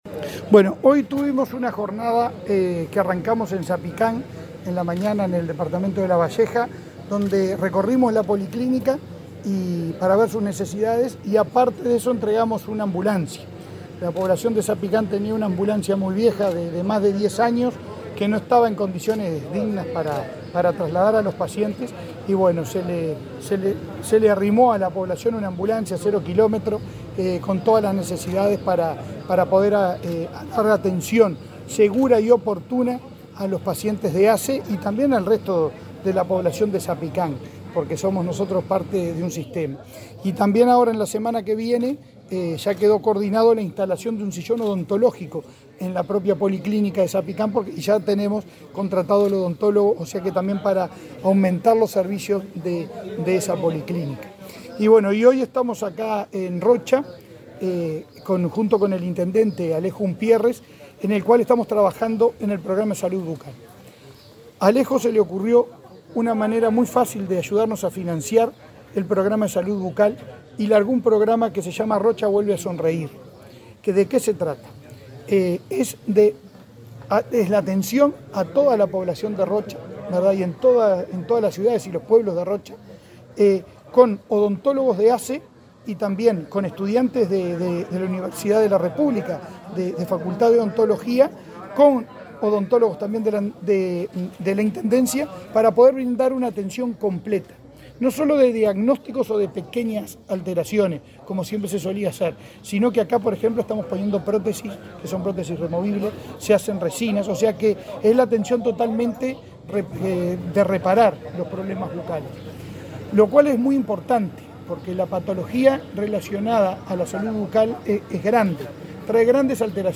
Entrevista al presidente de ASSE, Leonardo Cipriani